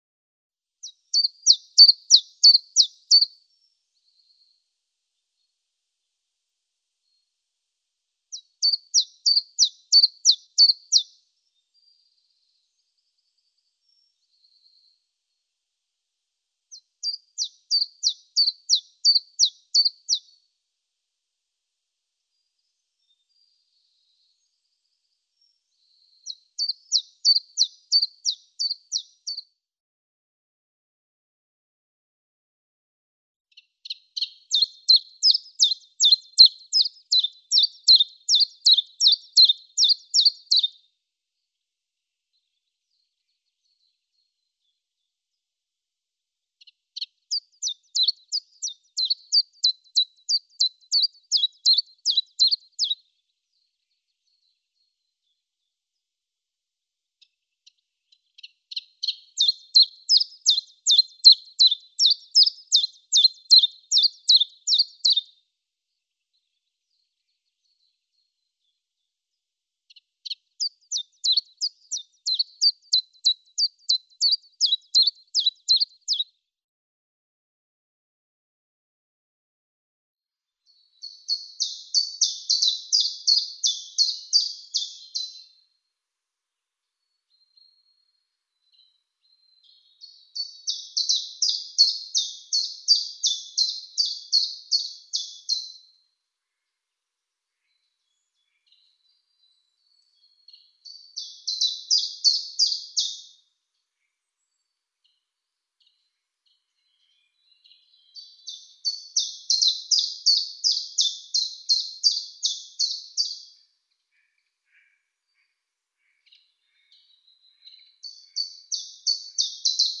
Phylloscopus_collybita_Track_65.mp3